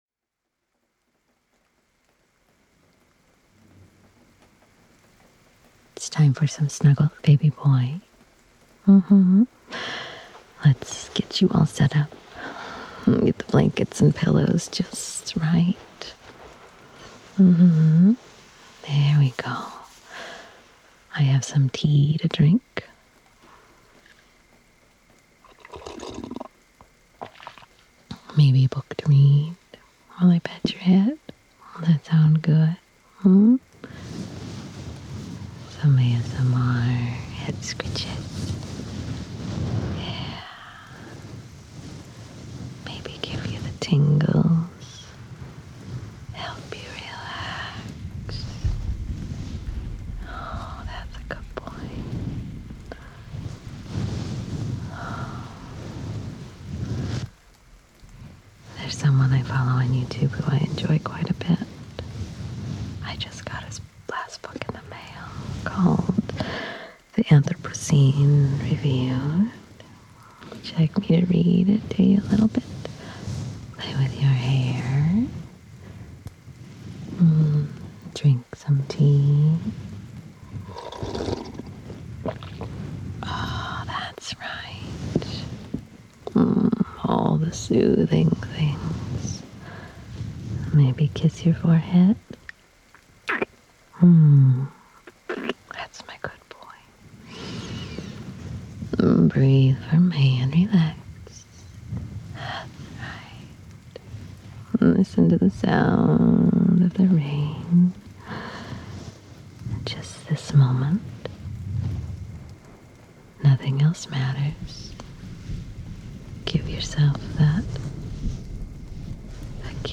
❤ Tingles and ASMR.